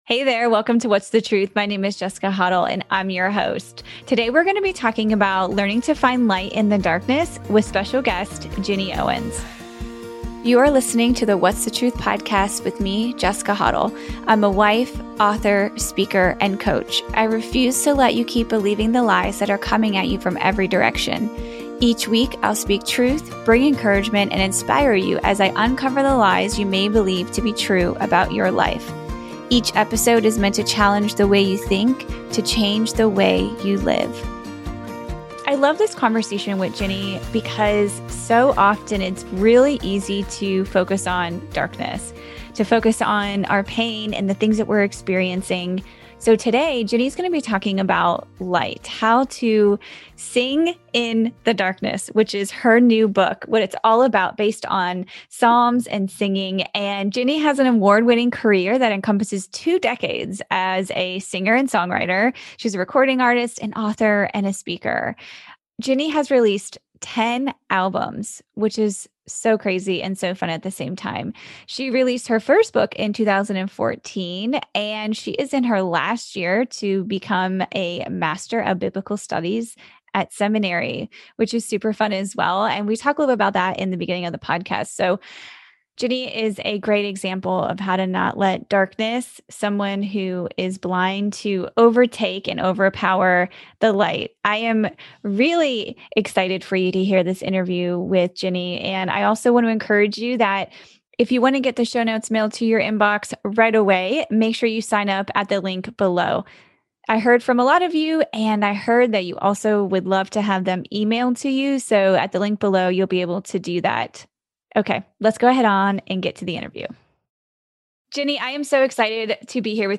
Facebook Twitter Headliner Embed Embed Code See more options In this episode, I’m excited to share my conversation with singer and author Ginny Owens about how we can learn to find light in the darkness.